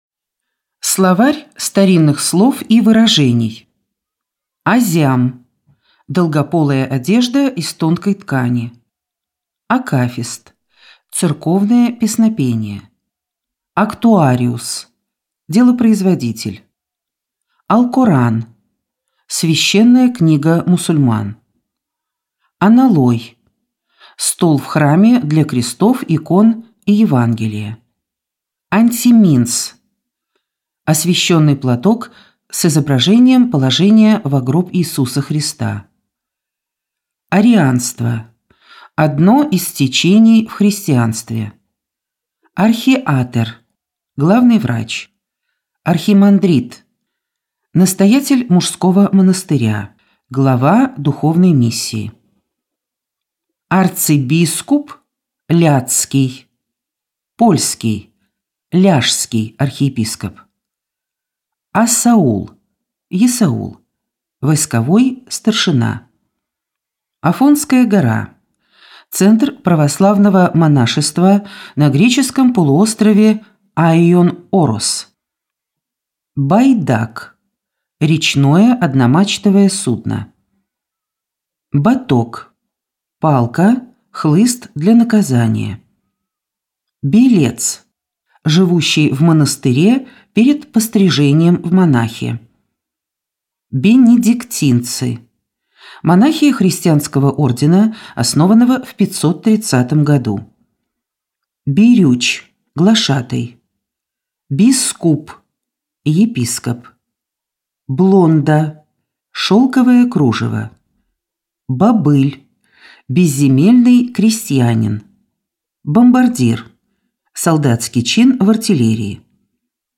Аудиокнига Великий князь и государь Иван Васильевич | Библиотека аудиокниг